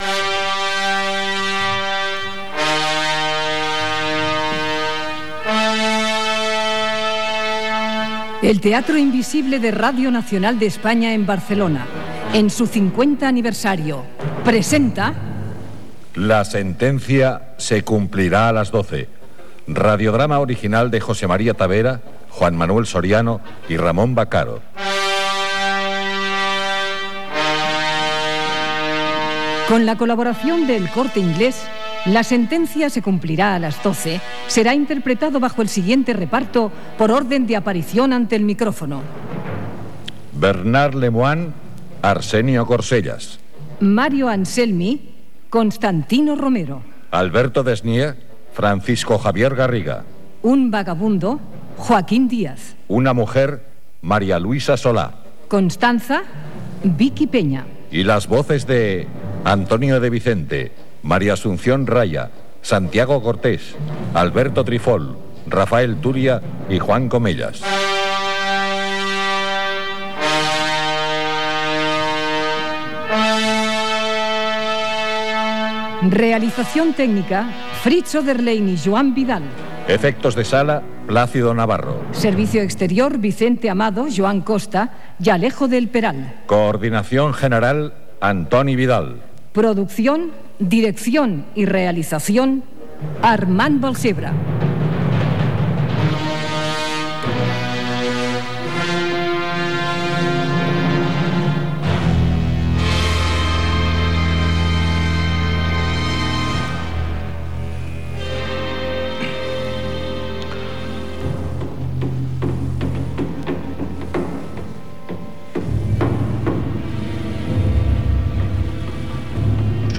"La sentencia se cumplirá a las doce". Careta i primers minuts.
Ficció
FM